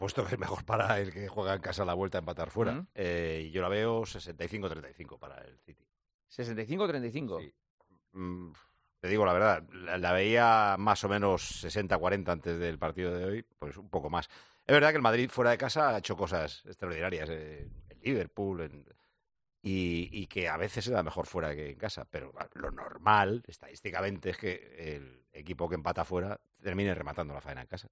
Paco González explica quién es su favorito para la vuelta entre el Manchester City y el Real Madrid
AUDIO: El director de 'Tiempo de Juego' revela en 'El Partidazo de COPE' lo que espera para el partido de vuelta de las semifinales de la Champions League